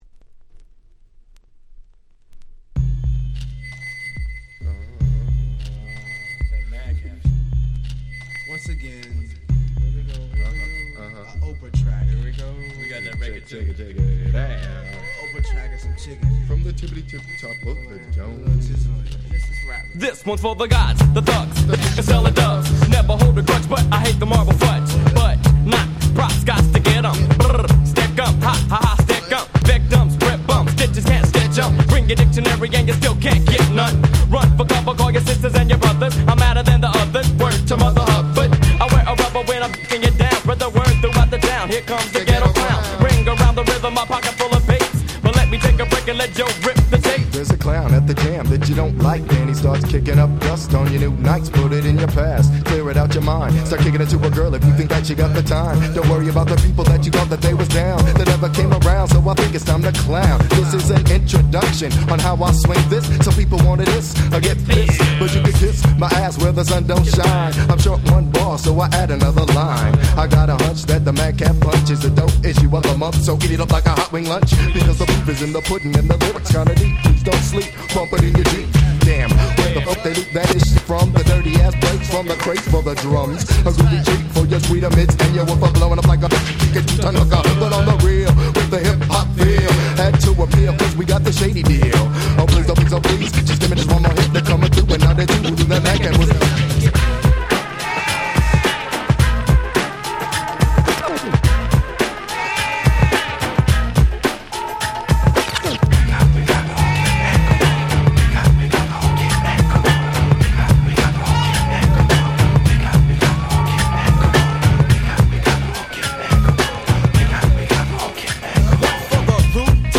93' Nice West Coast Hip Hop !!
LAのグループですが音的にはNYの音に近いかも。
BPM速目のボコボコなBeatに小気味の良いRapが非常に映えます！
問答無用のB級Hip Hop Classicsです！！